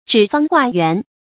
指方画圆 zhǐ fāng huà yuán
指方画圆发音
成语注音ㄓㄧˇ ㄈㄤ ㄏㄨㄚˋ ㄧㄨㄢˊ